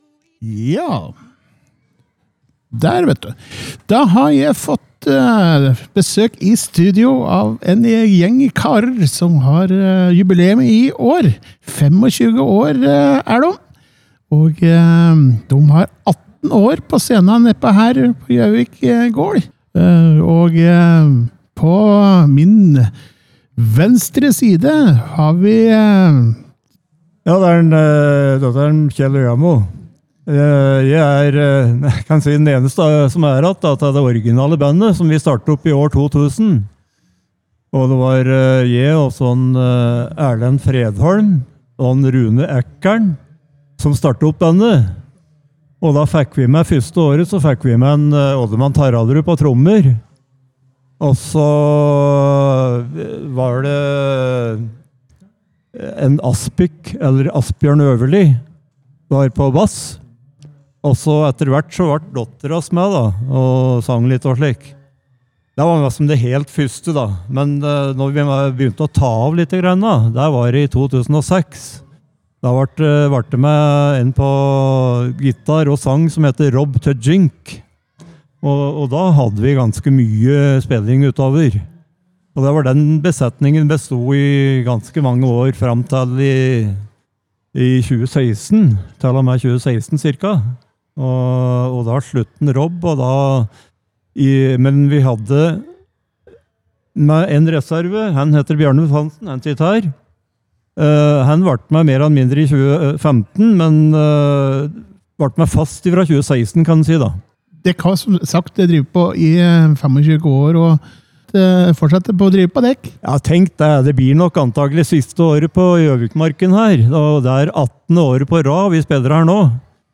Gutta i Yttervika- Bandet var innom vårt studio på Gjøvik Gård under Gjøvik Markens første dag.